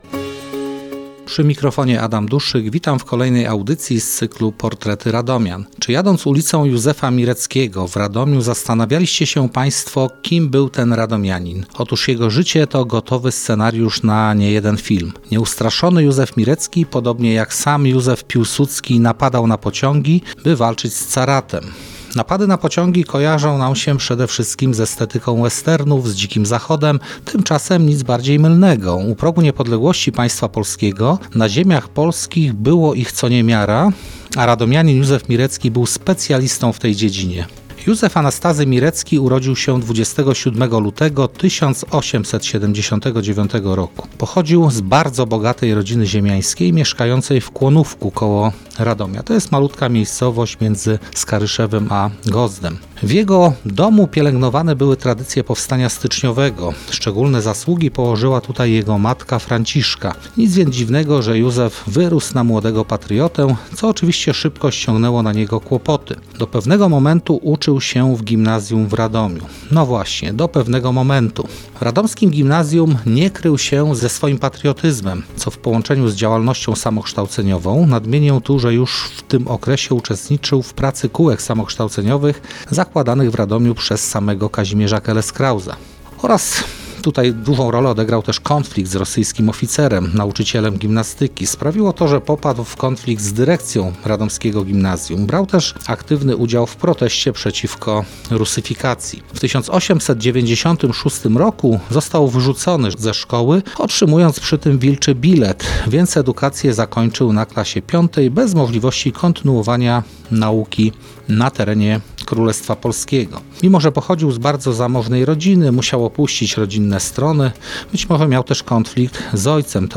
Wiadomości z Radomia i regionu, kultura, muzyka, kino, teatr, imprezy, koncerty – Radio Radom 87,7 FM!